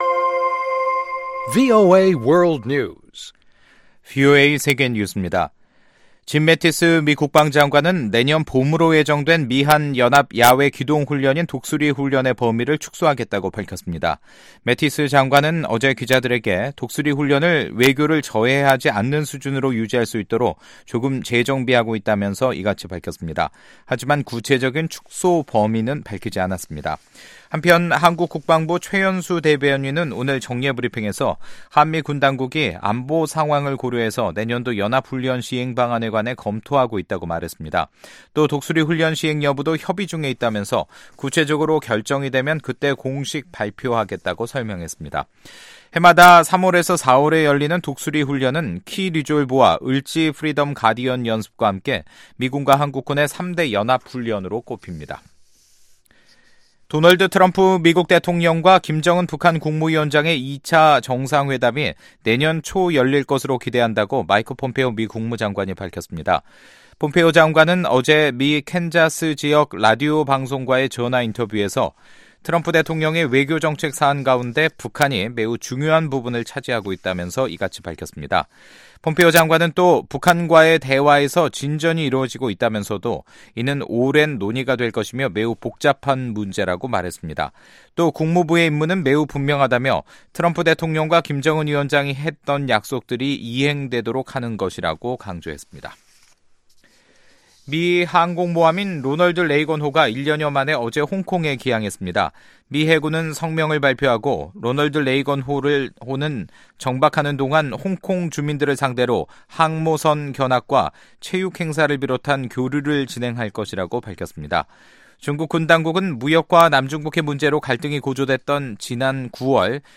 VOA 한국어 간판 뉴스 프로그램 '뉴스 투데이', 2018년 11월 22일 3부 방송입니다. 미국은 북한의 비핵화를 달성하기 위한 외교적 노력을 지원하기 위해 내년 봄 미-한 연합훈련인 독수리훈련을 축소하기로 했습니다. 마이크 폼페오 미국 국무장관은 북한과의 대화에서 진전이 이뤄지고 있으며, 미-북 2차 정사회담은 내년 초에 열릴 것으로 기대한다고 말했습니다. 최근 일부 대북지원 단체들이 제재로 인한 어려움을 호소해 온 가운데 유엔 안보리에서는 인도적 지원이 영향 받아선 안 된다는 의견이 제시된 것으로 전해졌습니다.